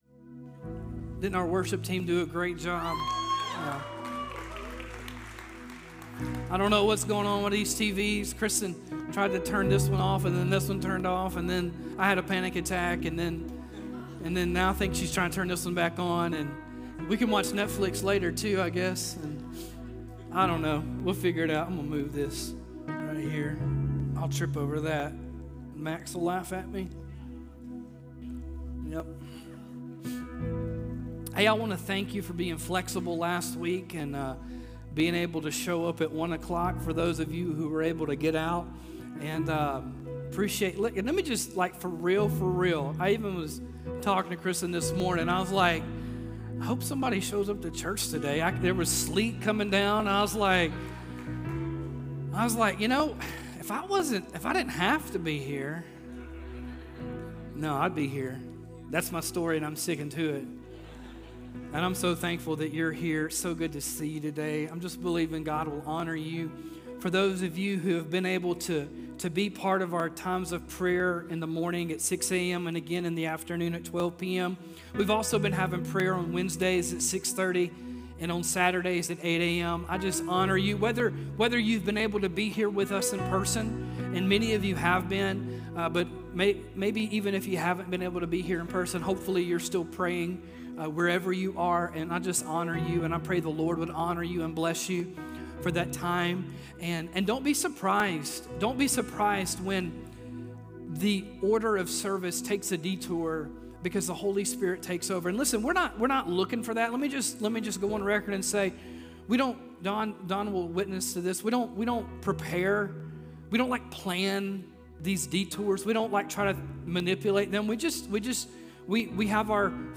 This message is based on 1 Samuel chapter 15.